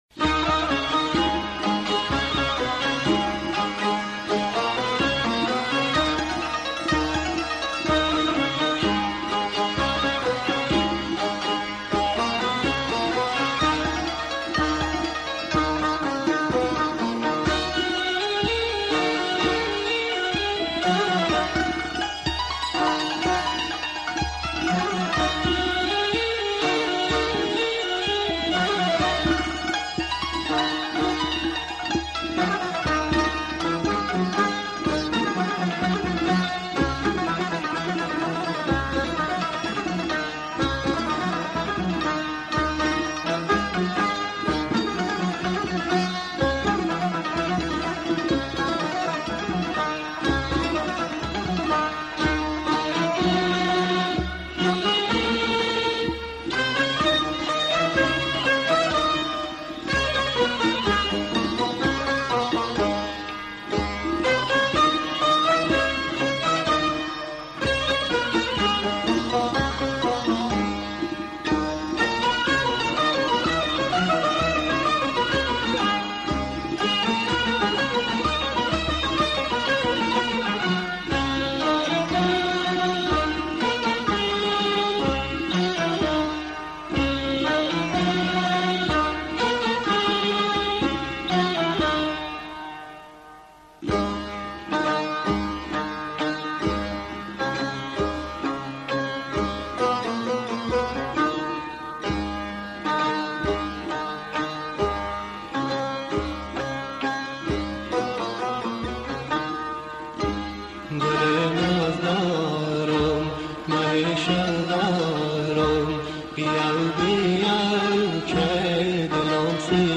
ترانه لری بختیاری